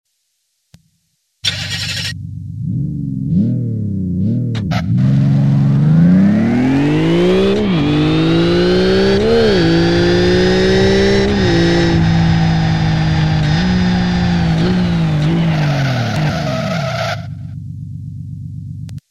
For those that haven't noticed, a starting engine sound has now been added.
I've basically bumped up the mids&highs (> 1.5 KHz) a lot to get rid of the "in-helmet" feeling. Then I've added more deep bass (80-160 Hz), while making the midbass (200-400 Hz) frequencies a little quieter in comparison to the other parts of the spectrum. The ~400 Hz frequencies (400 Hz = telephone tone) seemed way too loud to my ears before.